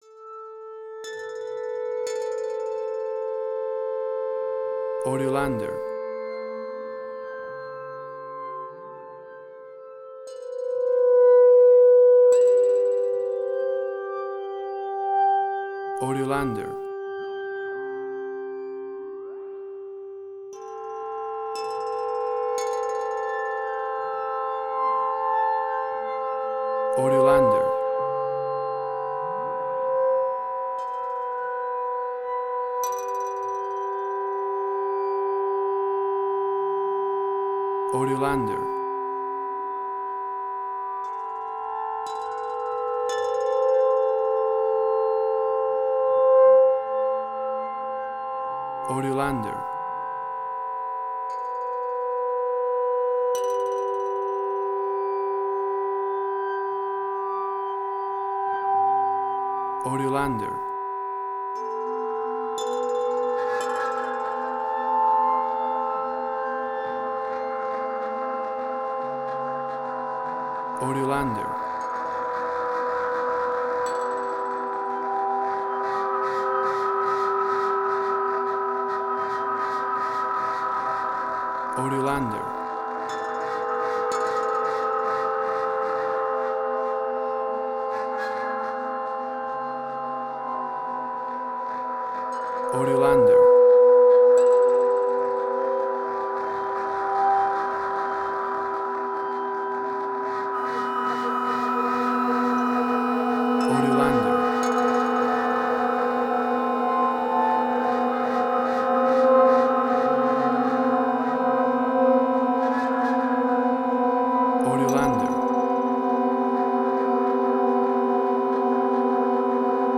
Dissonance
WAV Sample Rate: 16-Bit stereo, 44.1 kHz